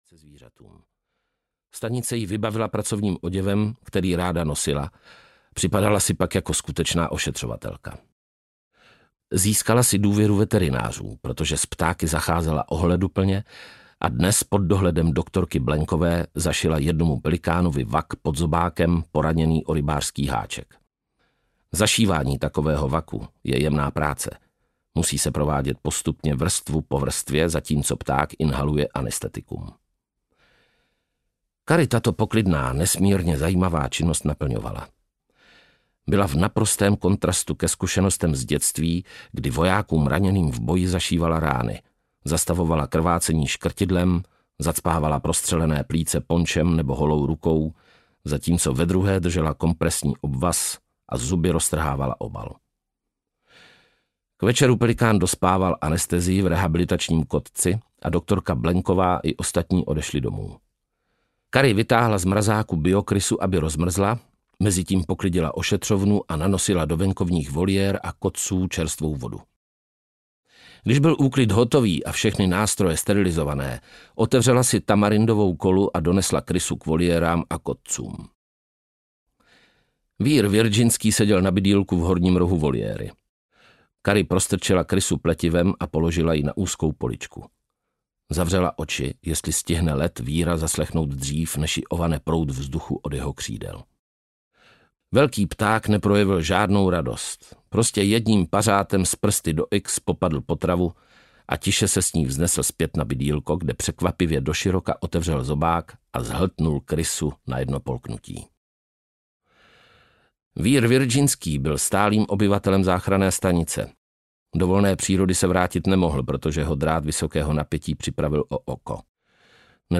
Cari Mora audiokniha
Ukázka z knihy
• InterpretPavel Nečas